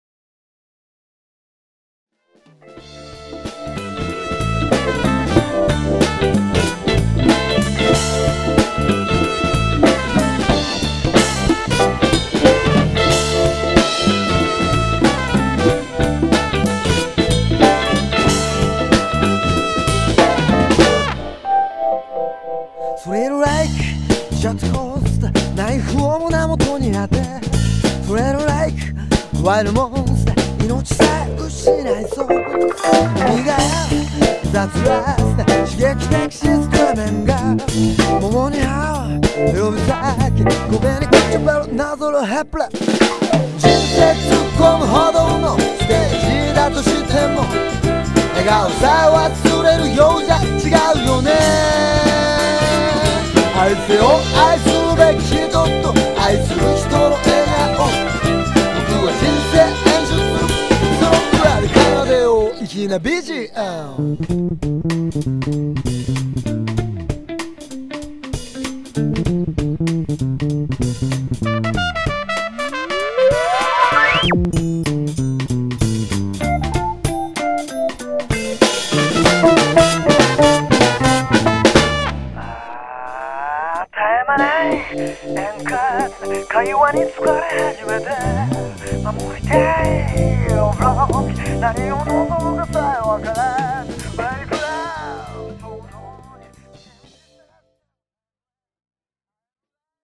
過去に録音していた音源を仮MIXですが随時UPしていきます！